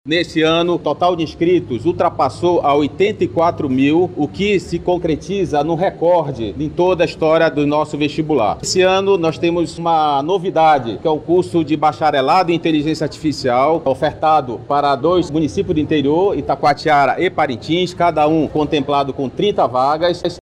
SONORA-3-VESTIBULAR-UEA-.mp3